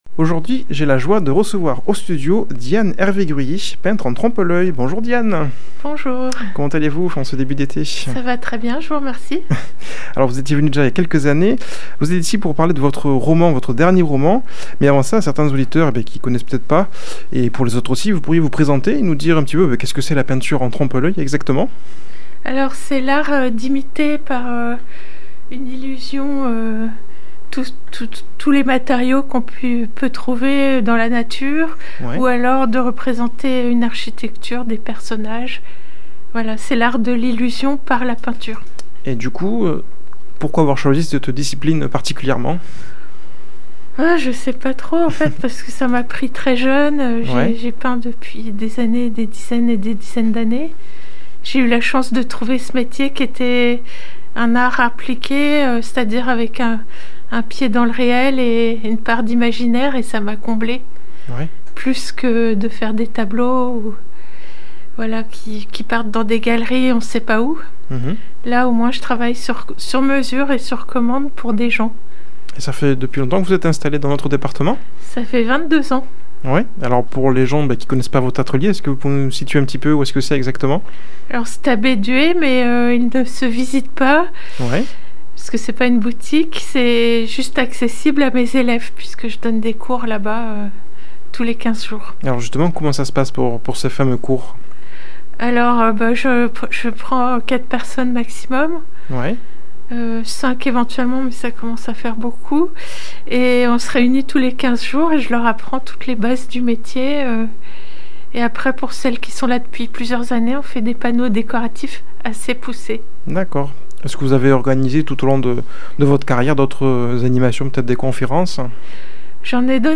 Une émission présentée par
Présentateur